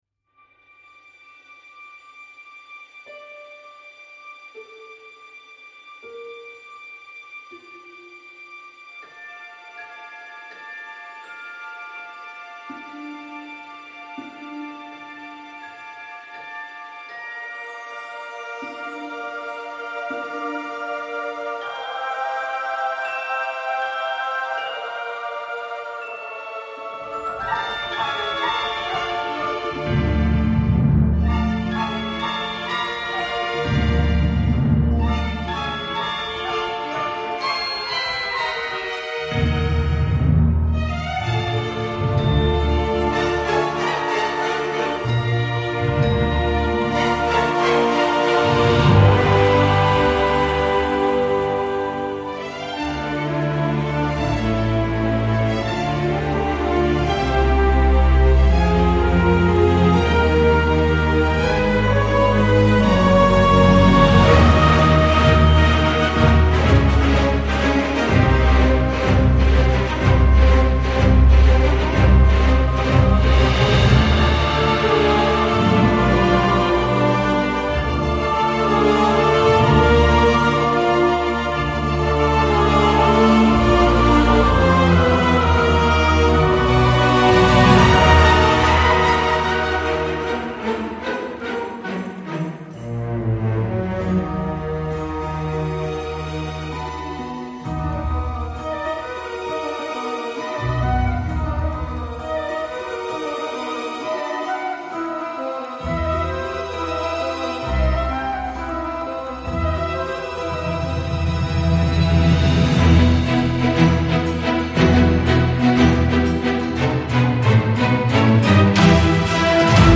C’est mignon mais on dirait une compile.